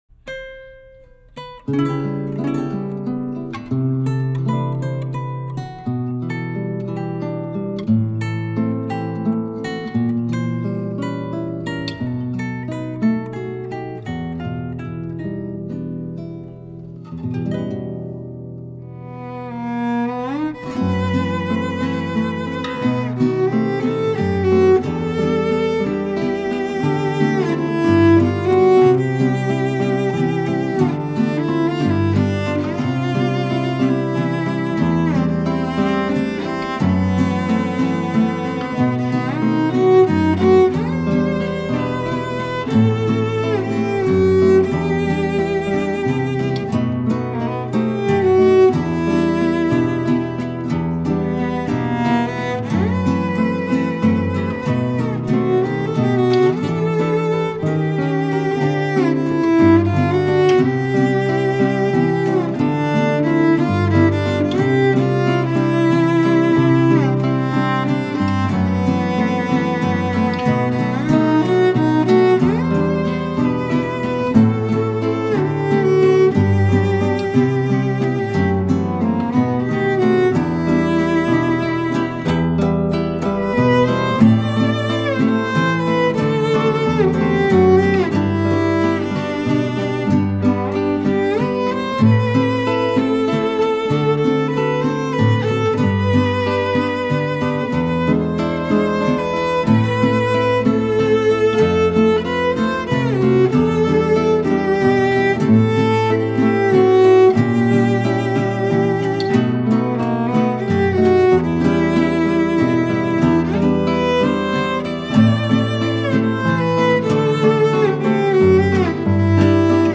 La música de América Latina.